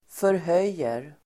Ladda ner uttalet
Uttal: [förh'öj:er]